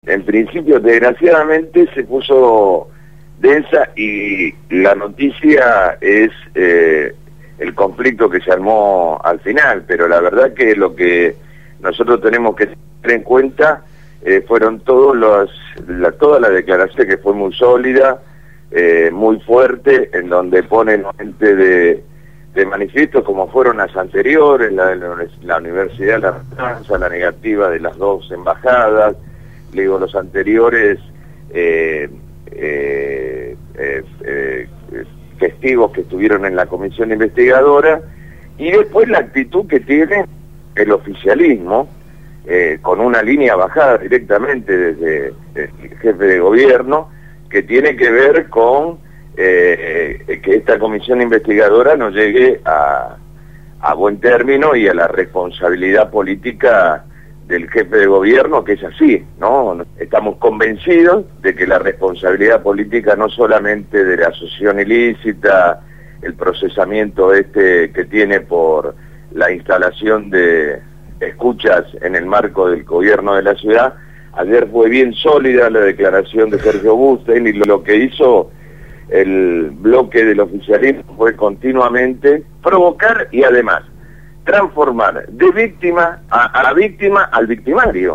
entrevistaron al Legislador del EPV Francisco «Tito» Nenna